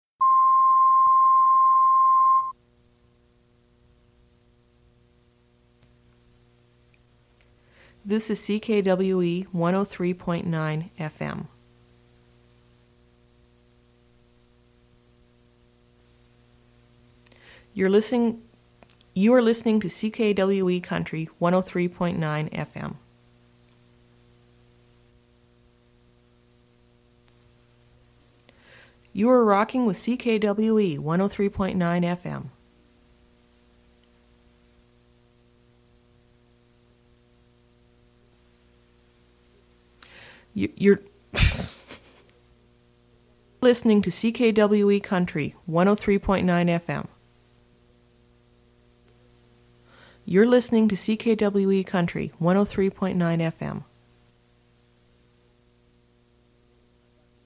Radio jingles